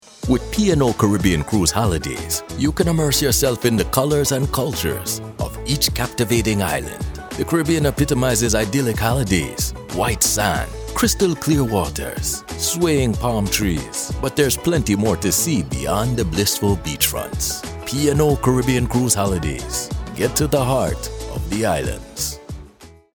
Authentic Jamaican and Caribbean Voiceover